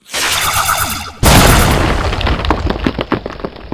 laser1.wav